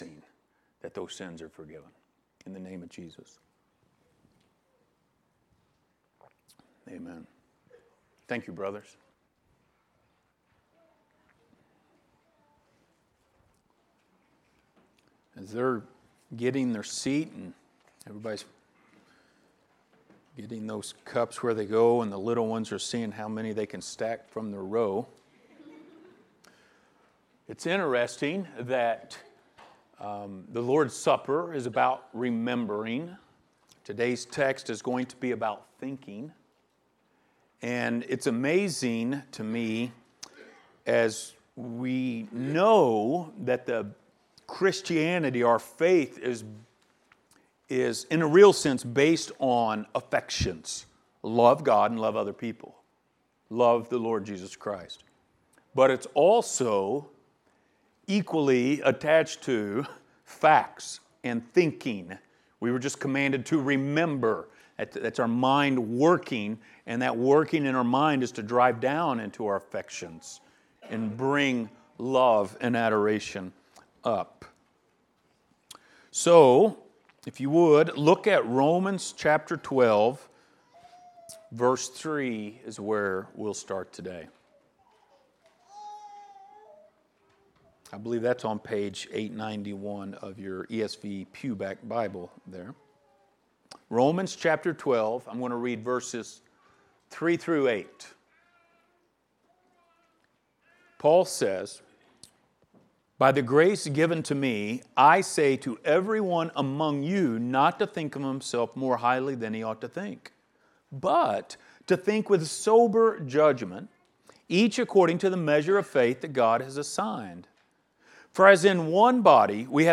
Book of Romans Passage: Romans 12:3-5 Service Type: Sunday Morning Related Topics